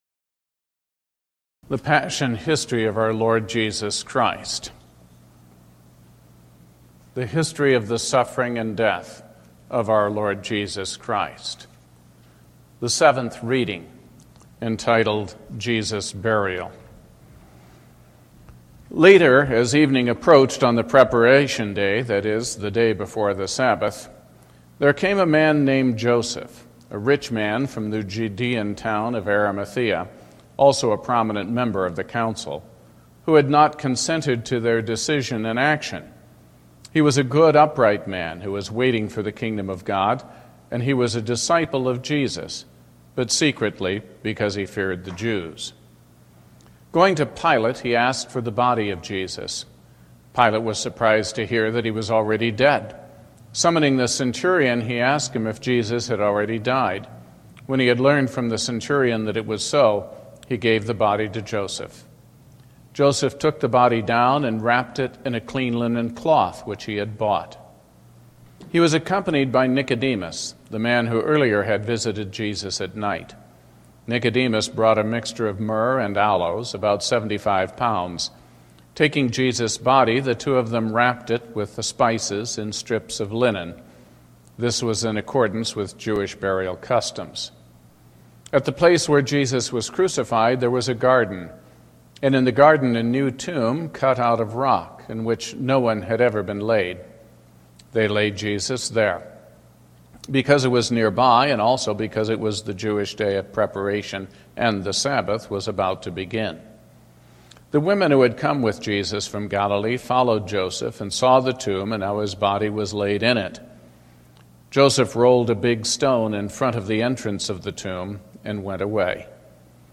Holy Week Readings Day 7 Jesus' Burial